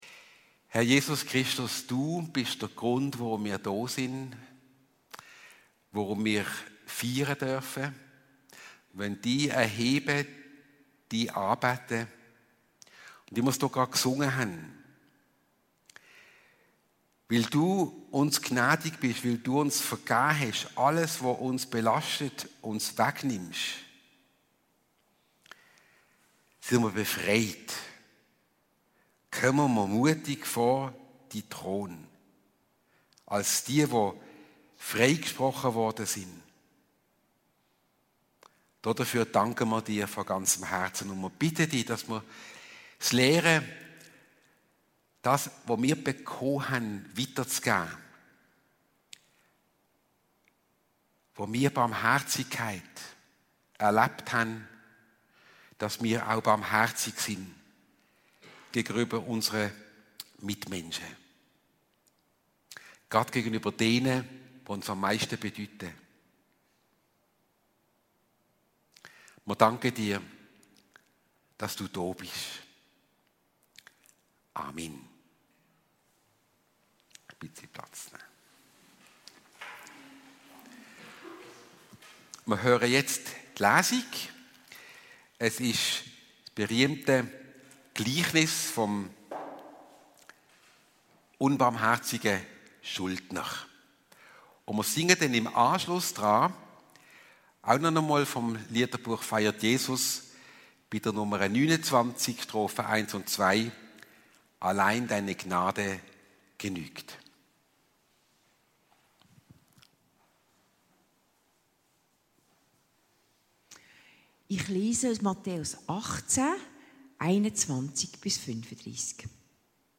Vergeben ist gar nicht so einfach und doch ist sie die Voraussetzung für heile Beziehungen. Gary Chapman, der mit seinen fünf Sprachen der Liebe bekannt geworden ist, hat ebenso fünf verschiedene Sprache der Vergebung beschrieben, die wir im Rahmen der Predigt kennen lernen werden.